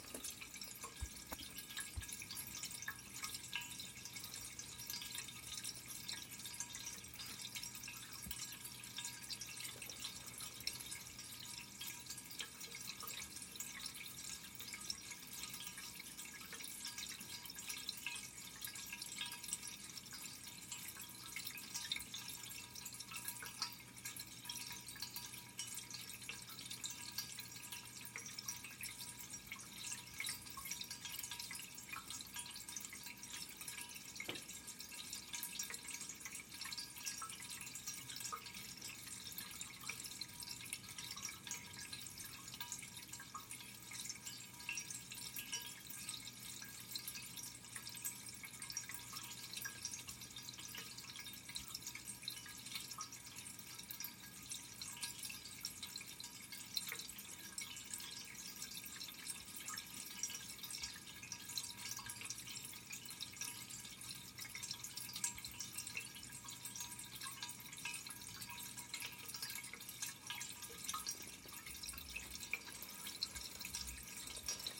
Pouring water into cup
描述：Pouring cold water into a fine tea cup.This sound was recorded in a studio at 48Hz 24 bit Mono with a NT 5 microphone.
标签： fine cold delicate water pour tea cup liquid OWI Pouring